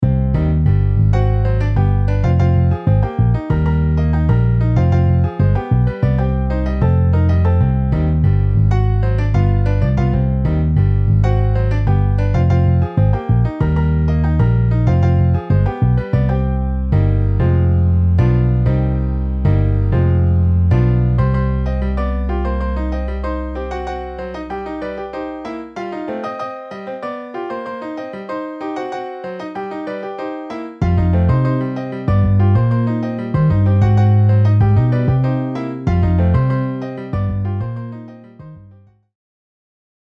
Flute, Tenor Sax, 2X Trombones, Violin, Piano, Bass